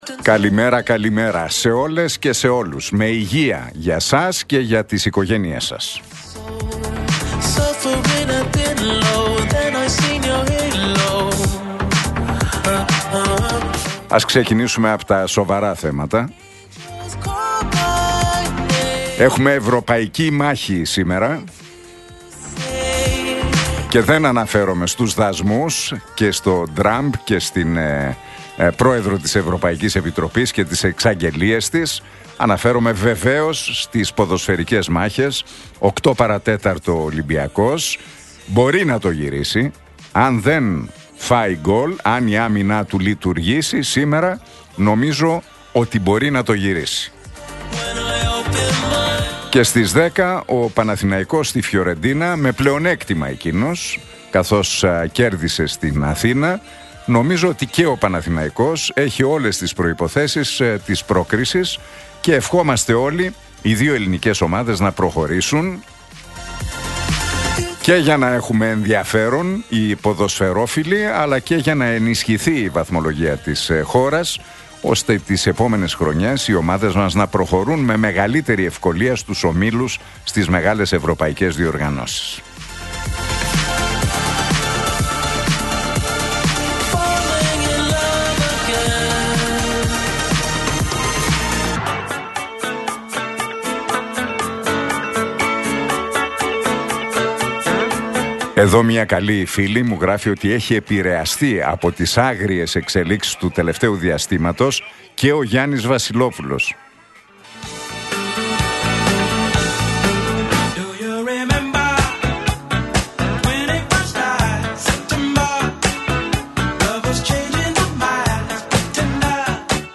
Ακούστε το σχόλιο του Νίκου Χατζηνικολάου στον ραδιοφωνικό σταθμό RealFm 97,8, την Πέμπτη 13 Μαρτίου 2025.